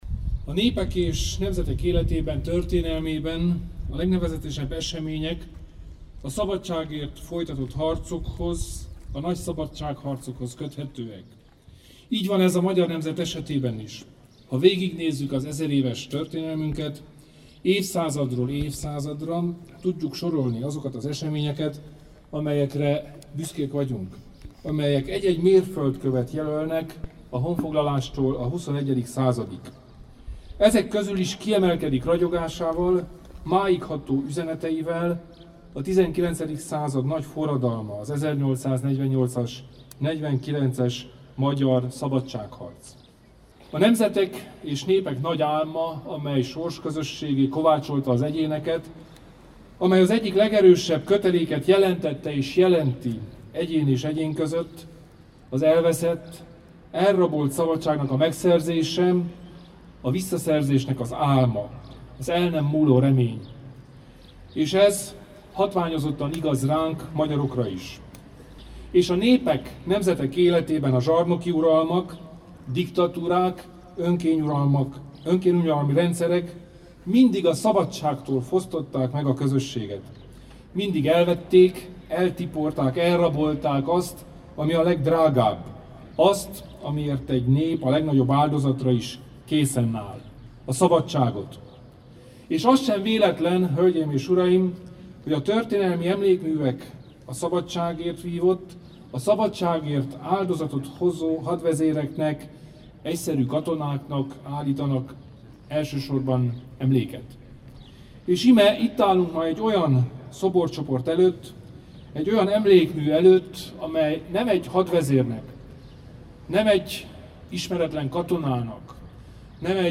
A teljes beszéd meghallgatható itt.
Kelemen_beszede_a_Szabadsag-szobor_10_evfordulojan.mp3